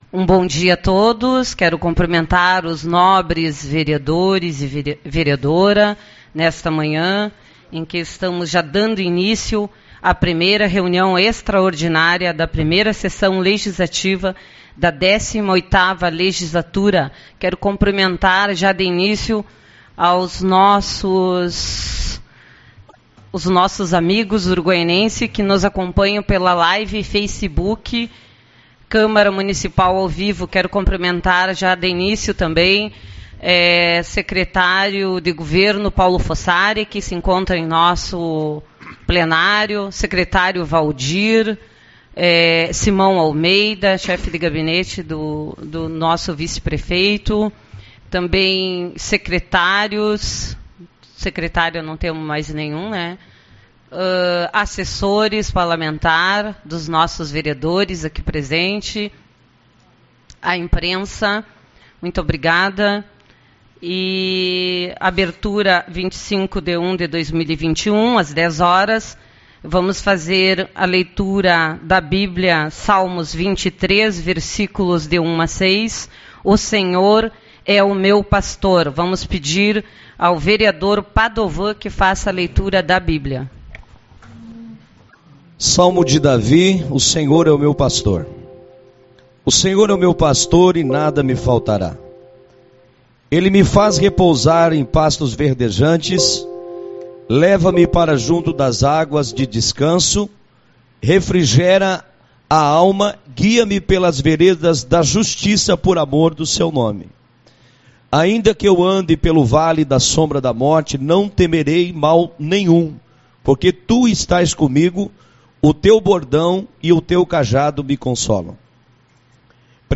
25/01 - Reunião Representativa